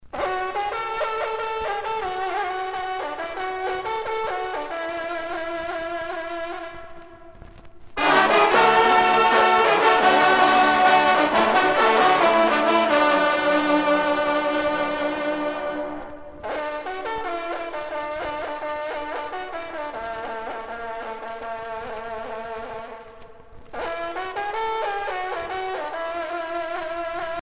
sonnerie vénerie - fanfare d'équipage
Pièce musicale éditée